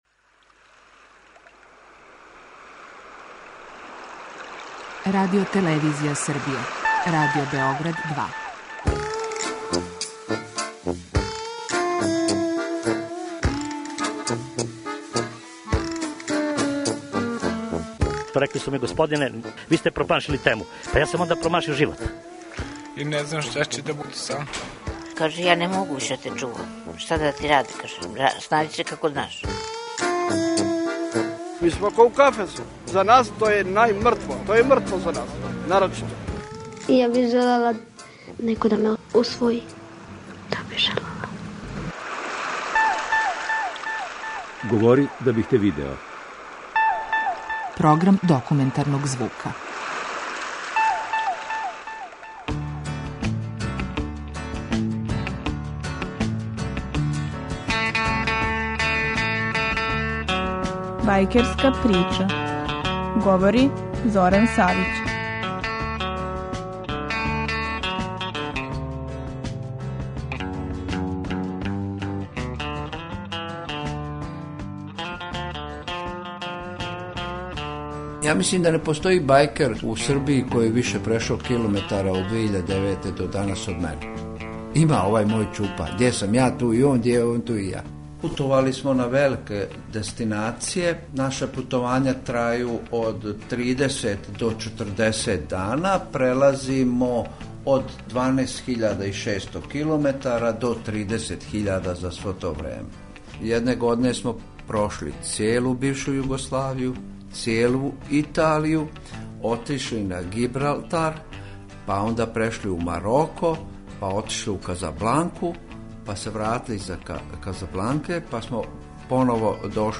Слушаћете репортажу 'Бајкерска прича' (први део)
DOKUMENTARNI.mp3